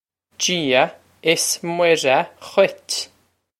Pronunciation for how to say
Jee-ah iss Mwir-uh ghwitch
This is an approximate phonetic pronunciation of the phrase.